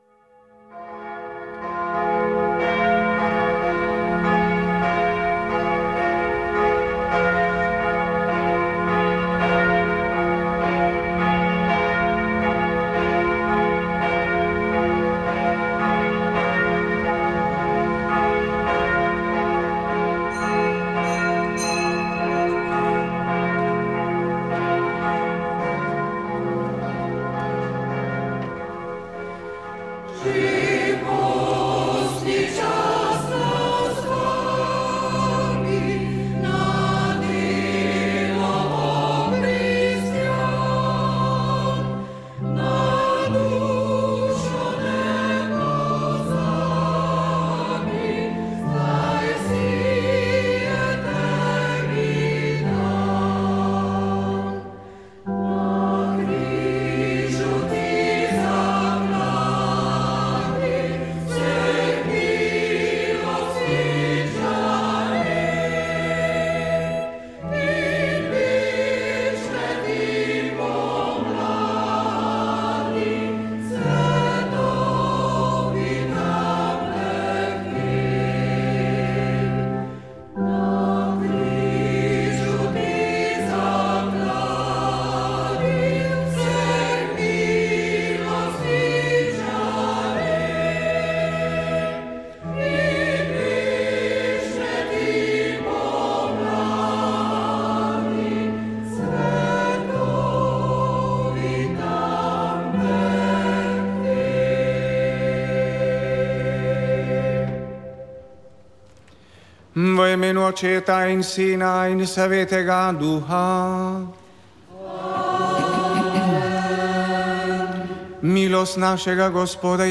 Sveta maša
Sv. maša iz cerkve Marijinega oznanjenja na Tromostovju v Ljubljani 13. 4.